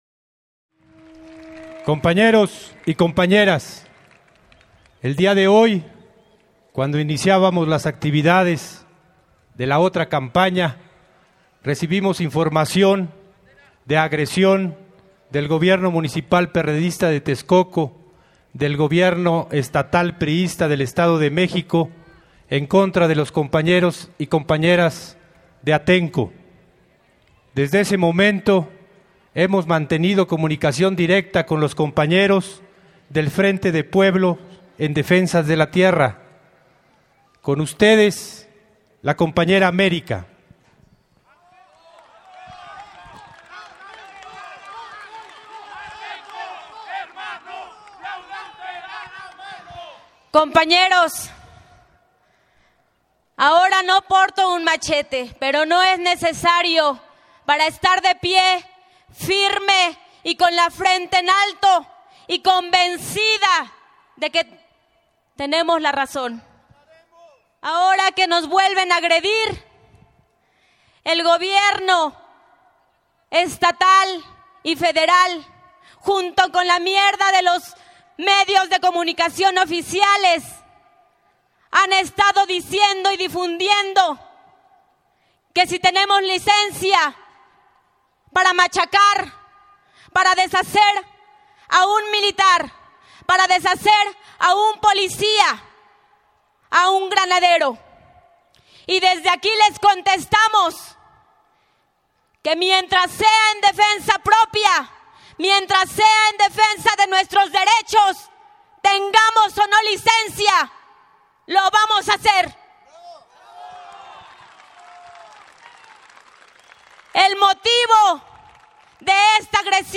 Liens audio vers le discours d’appel du FPDT et du Delegado Zero Photos de l’affrontement Le FPDT rend responsable de ces actes le gouverneur de Mexico, Enrique Pena Nieto, ainsi que le président Vincente Fox.